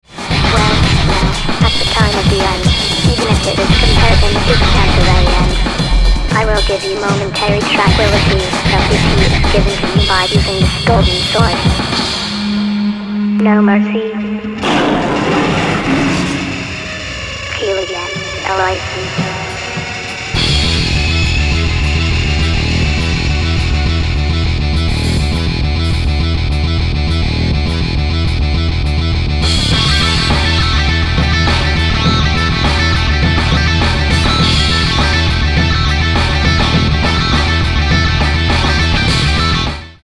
レコーディングスタジオ : JEO